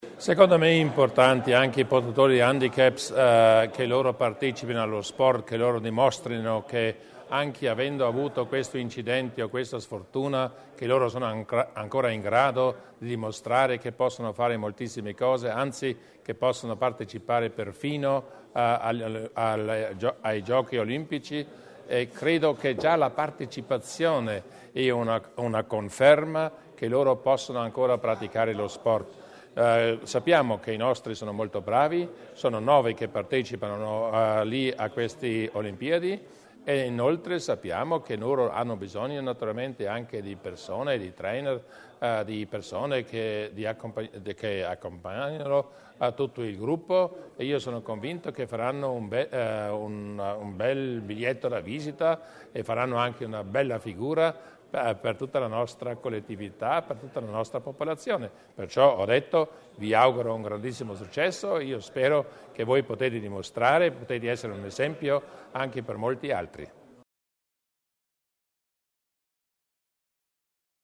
Il saluto del Presidente Durnwalder agli atleti paralimpici